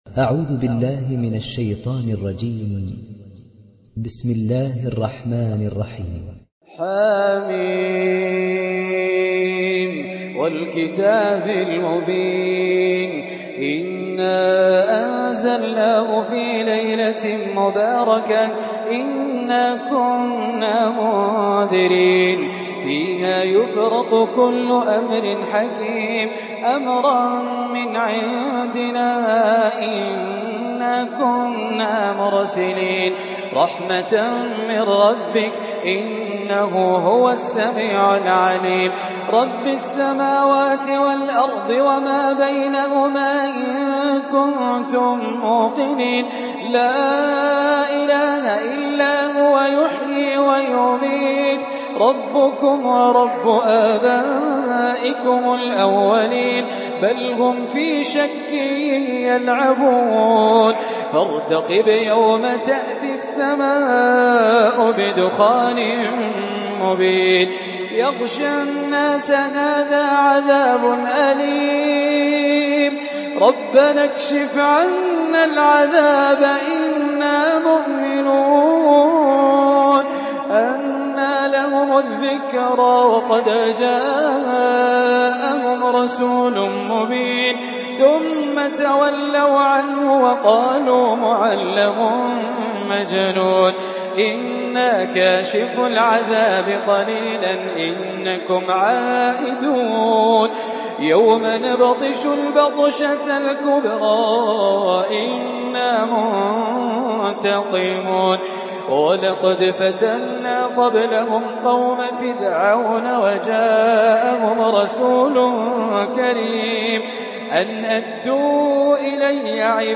Riwayat Hafs from Asim